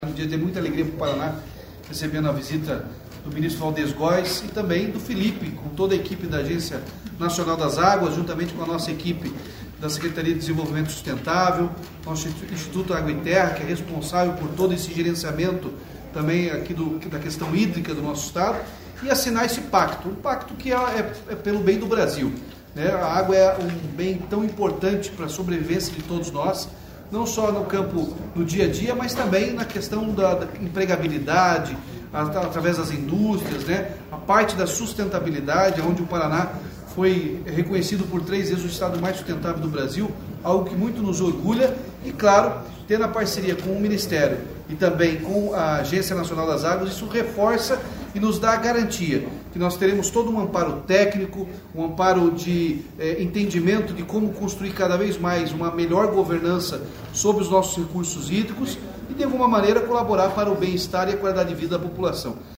Sonora do governador Ratinho Junior sobre a adesão do Paraná ao Pacto pela Governança da Água